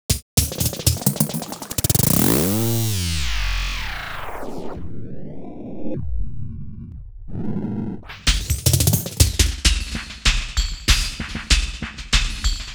98WAGONFX2-R.wav